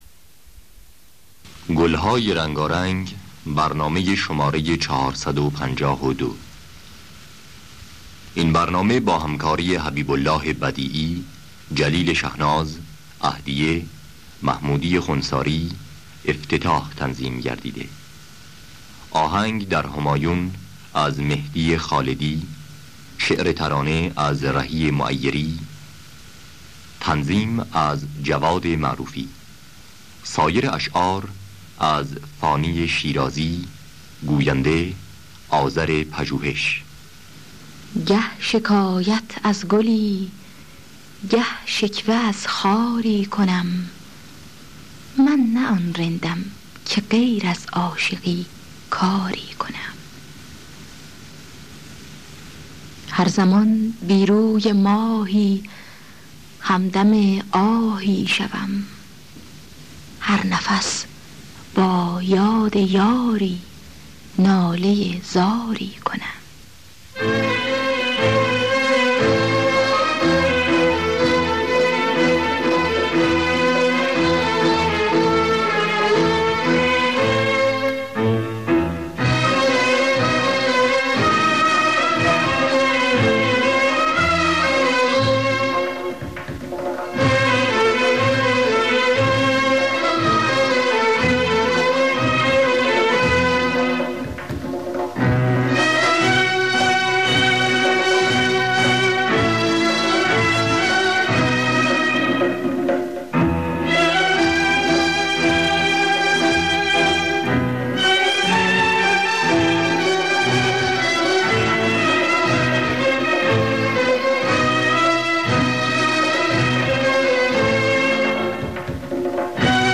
دانلود گلهای رنگارنگ ۴۵۲ با صدای عهدیه، محمودی خوانساری در دستگاه همایون.
خوانندگان: عهدیه محمودی خوانساری